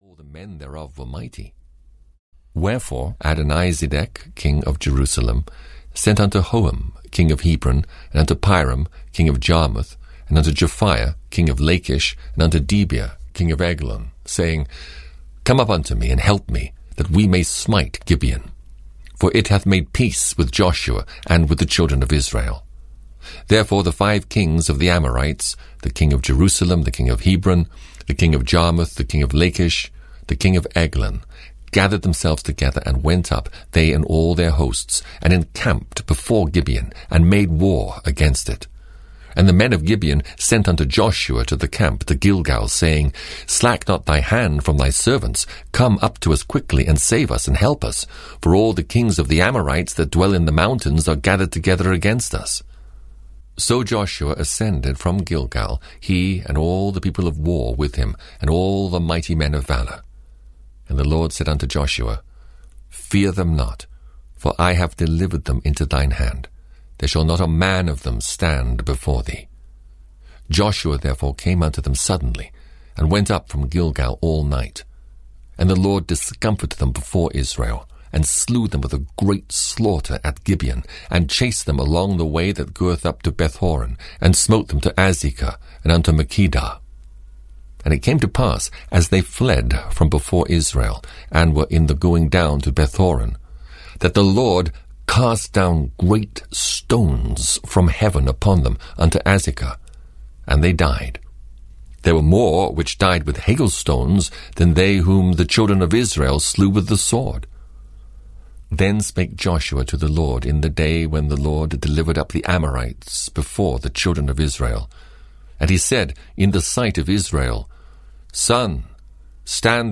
Audio knihaThe Old Testament 6 - Joshua (EN)
Ukázka z knihy